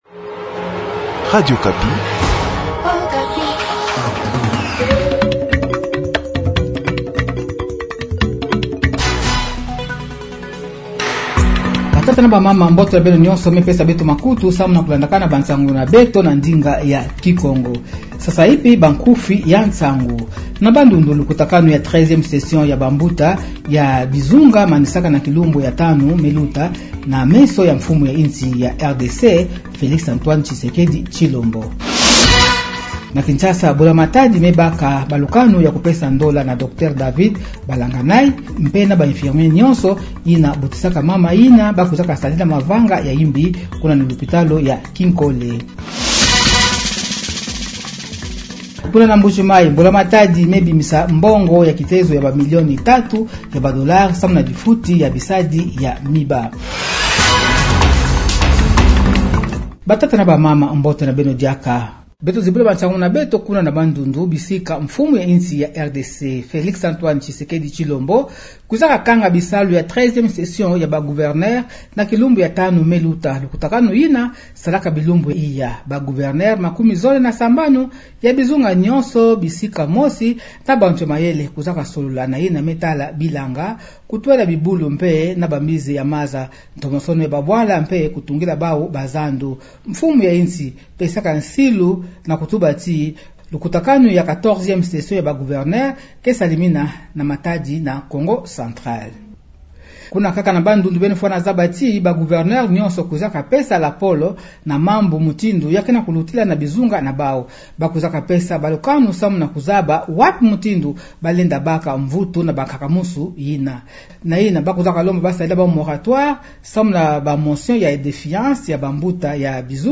Journal Kikongo matin